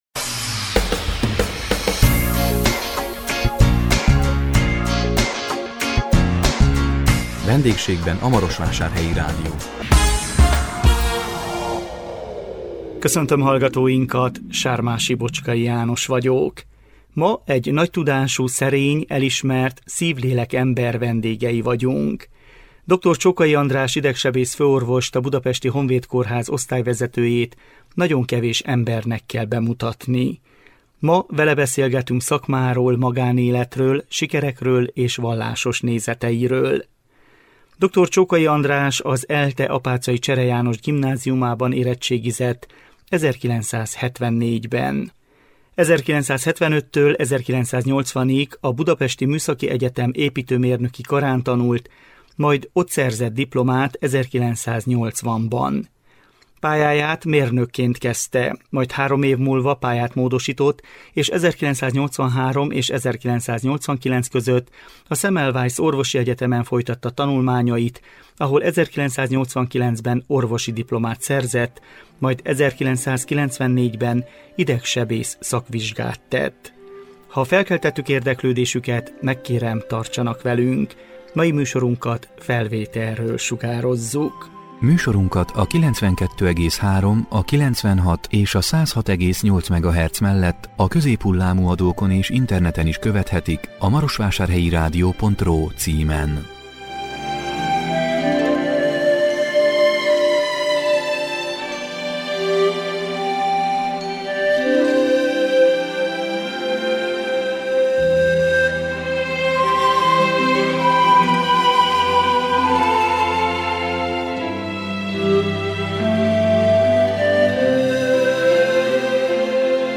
Vele beszélgettünk szakmáról, magánéletről, sikerekről és vallásos nézeteiről.